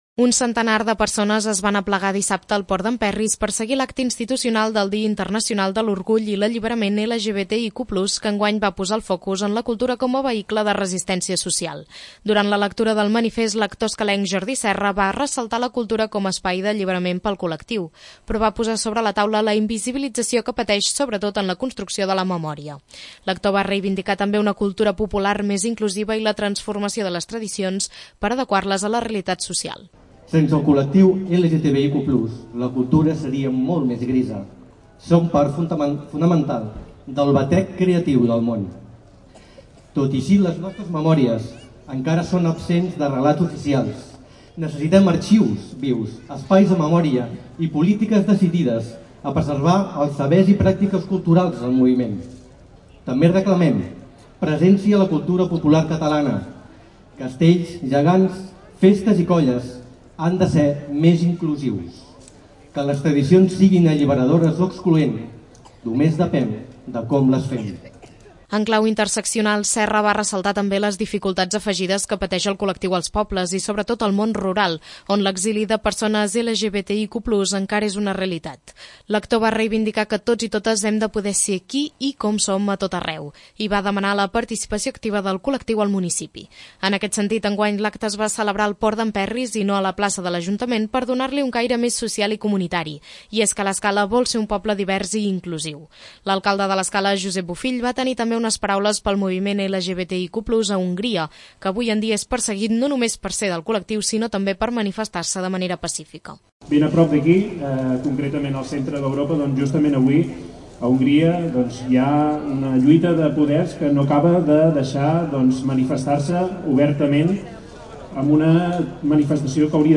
L'Escala va celebrar el dia Internacional de l'Orgull i l'Alliberament LGBTIQ+ amb un acte al port d'en Perris que va reivindicar la cultura com a vehicle de resistència social i va reclamar una cultura popular més inclusiva.
Un centenar de persones es van aplegar dissabte al Port d'en Perris per seguir l'acte institucional del dia Internacional de l'Orgull i l'Alliberament LGBTIQ+ que enguany va posar el focus en la cultura com a vehicle de resistència social.